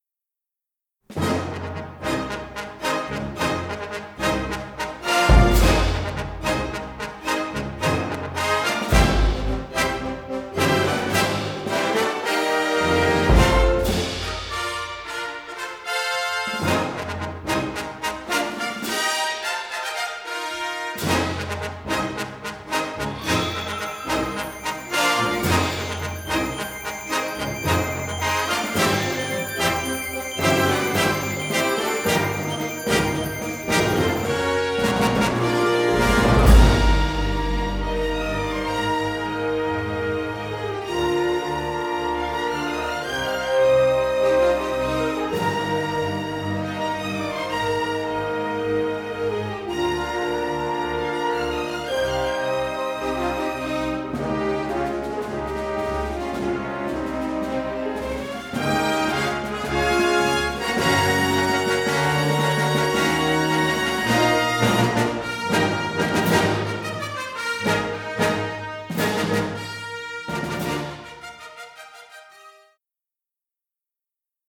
dynamic fortissimo rhythmic motif for trombones
Unusually crisp, punchy recording